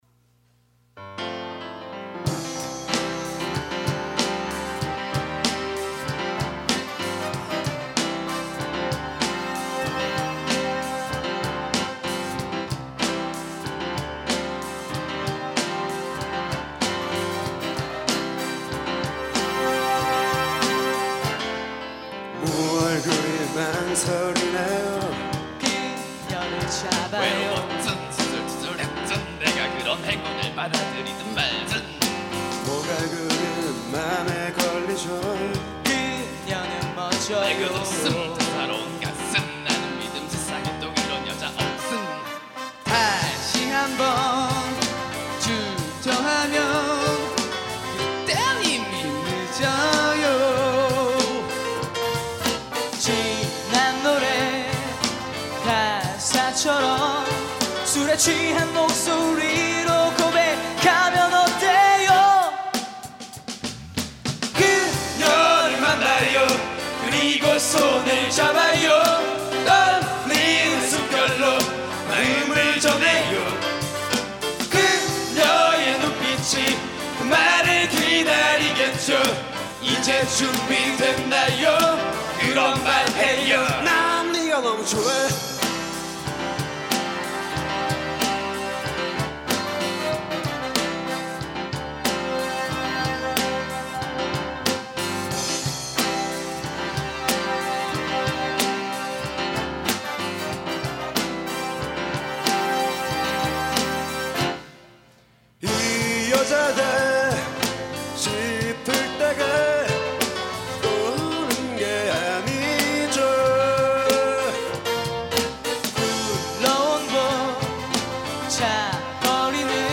2005년 신입생 환영공연
홍익대학교 신축강당
베이스
드럼
신디사이저